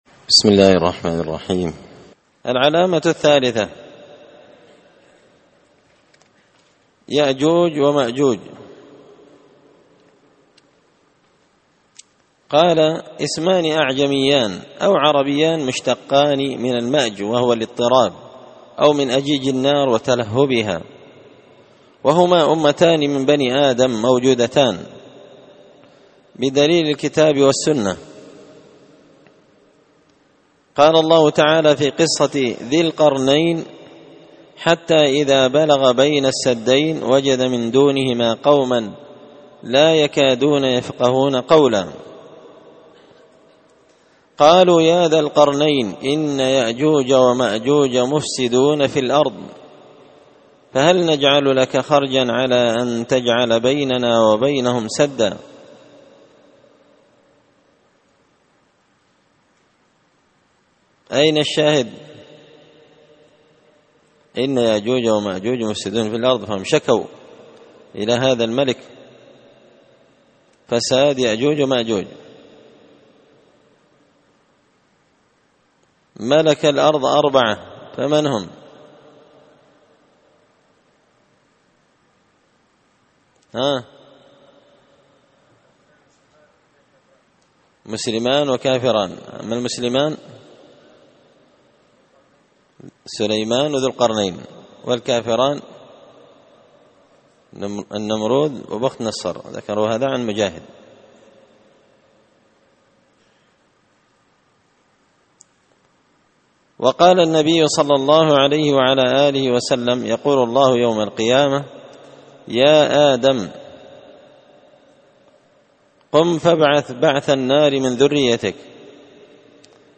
شرح لمعة الاعتقاد ـ الدرس 35
دار الحديث بمسجد الفرقان ـ قشن ـ المهرة ـ اليمن